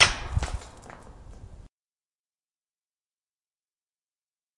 投掷石块
描述：记录小石头被抛出和玩耍。
标签： 岩石 OWI 运动 投掷
声道立体声